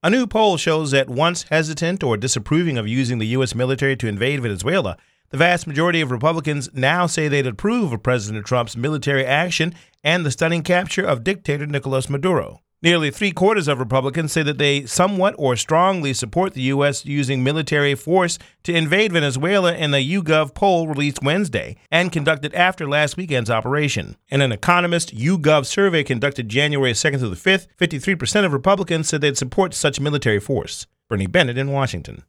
Salem Radio Network News Thursday, April 2, 2026